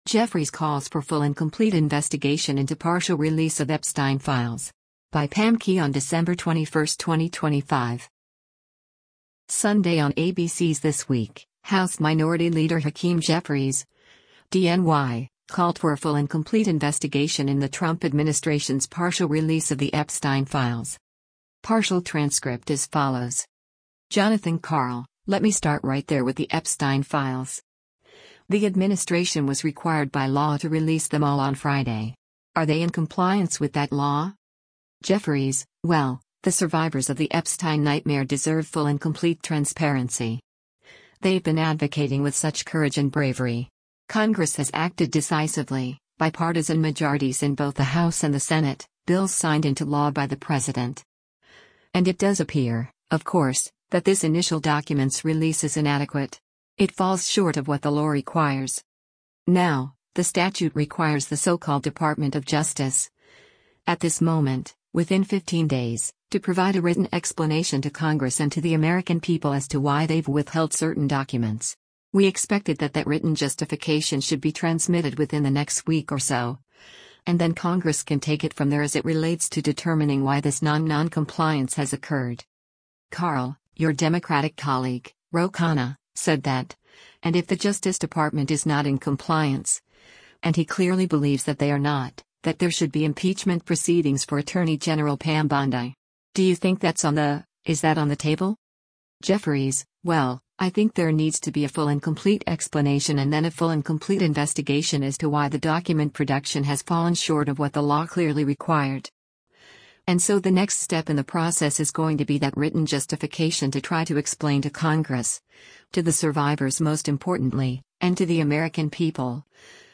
Sunday on ABC’s “This Week,” House Minority Leader Hakeem Jeffries (D-NY) called for a “full and complete investigation” in the Trump administration’s partial release of the Epstein files.